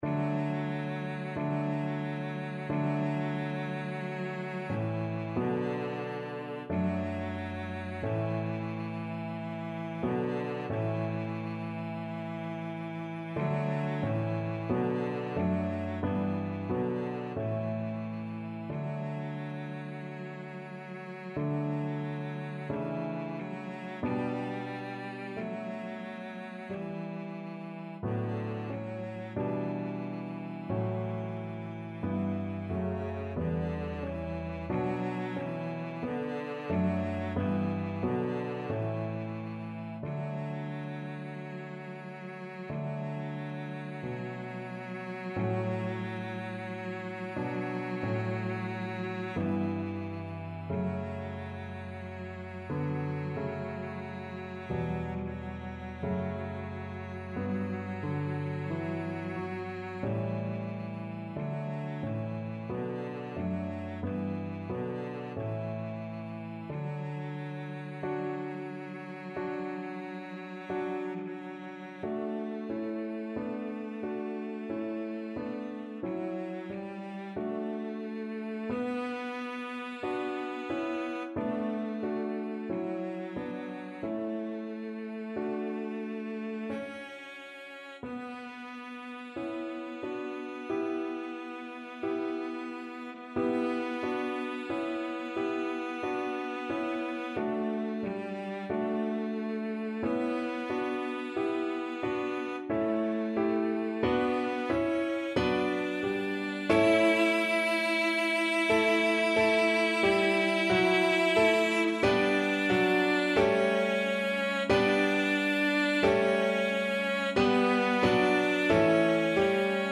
Cello version
4/4 (View more 4/4 Music)
Smoothly, not quick =45
Db4-E5
Classical (View more Classical Cello Music)